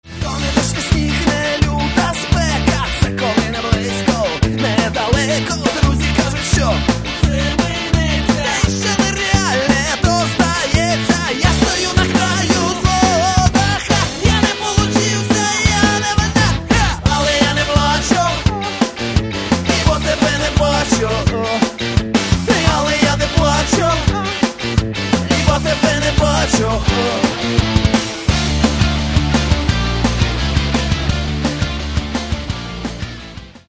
Каталог -> Рок и альтернатива -> Сборники